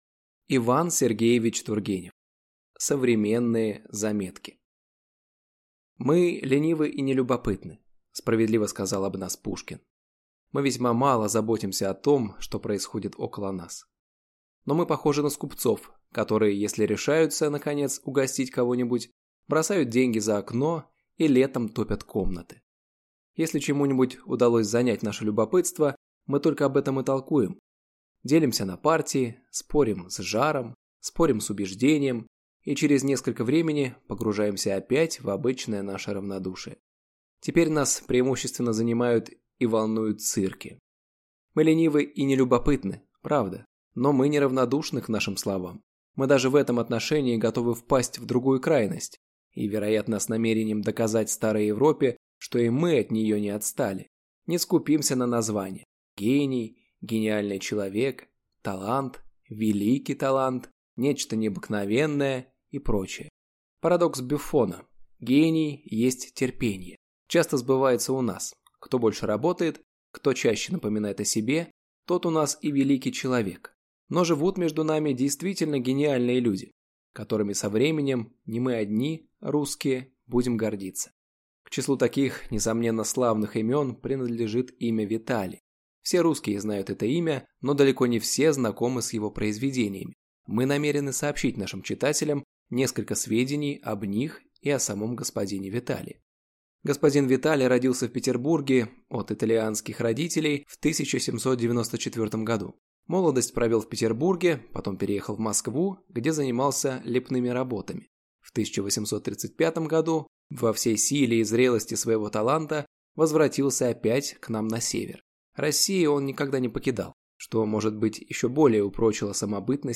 Аудиокнига Современные заметки | Библиотека аудиокниг